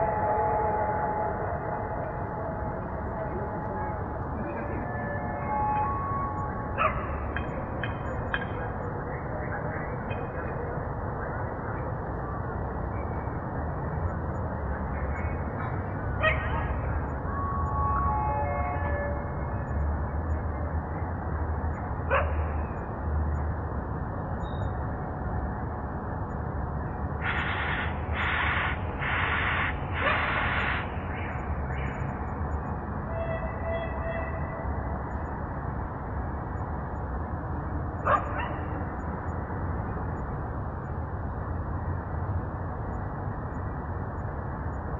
描述：录音：在火车站，火车停了，比走铁路的气氛还要好
Tag: 铁路 车站 列车